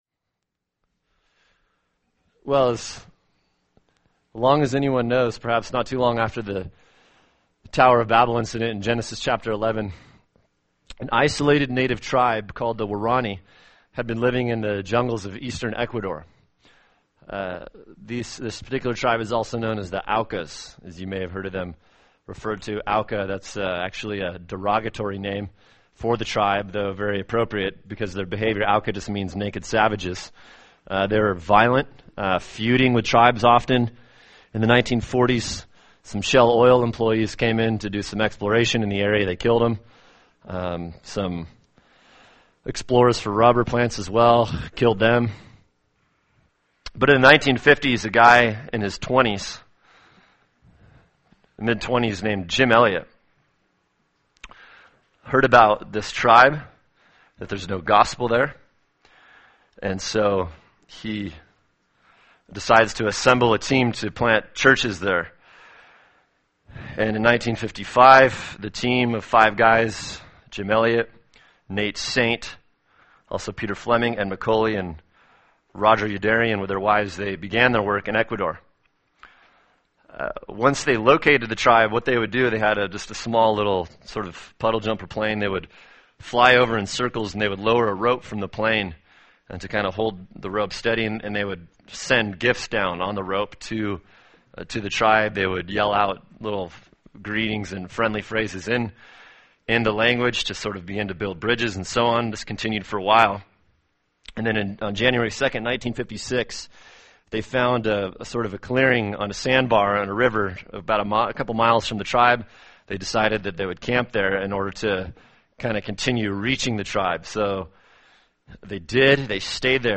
[sermon] Matthew 5:43-48 “Grace for Humans” | Cornerstone Church - Jackson Hole